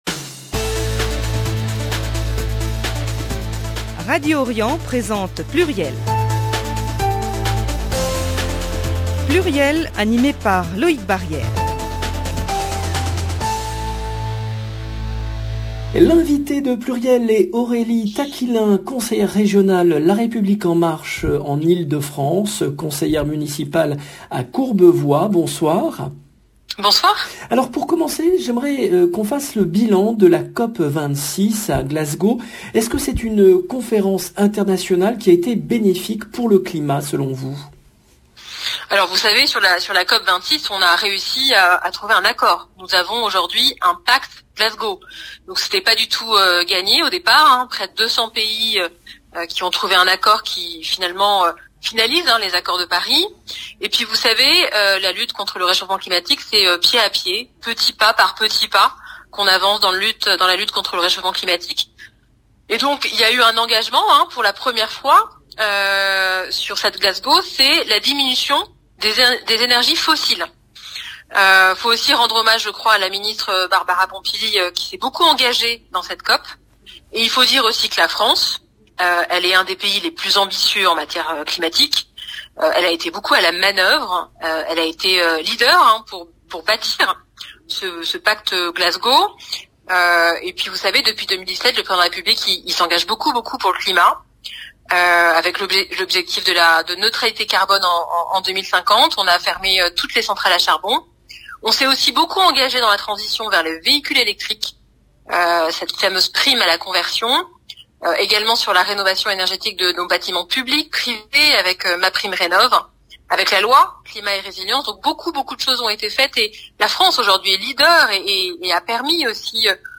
PLURIEL, le rendez-vous politique du mardi 16 novembre 2021